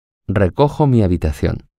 11.6 PRONUNCIACIÓN Y ORTOGRAFÍA
En español, las vocales juntas de palabras distintas se pronuncian en una misma secuencia, en una misma sílaba: